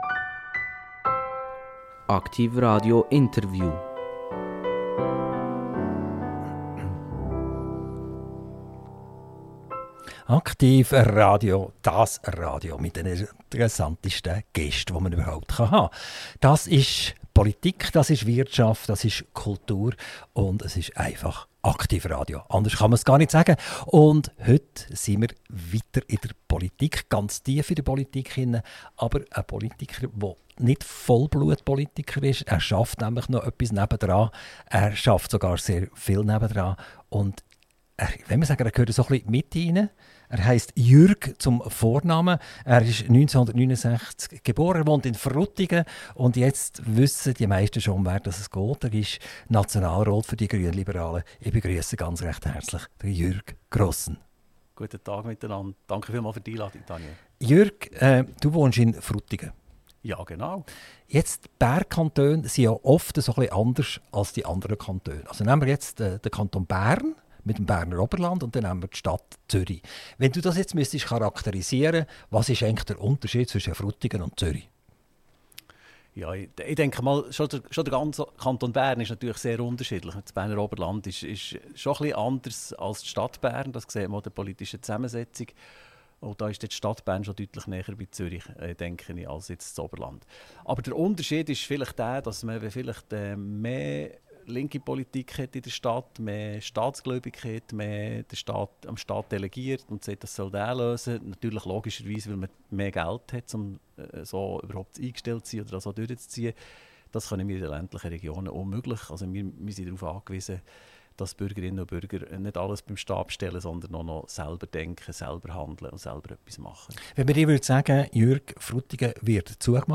INTERVIEW - Jürg Grossen - 04.11.2025 ~ AKTIV RADIO Podcast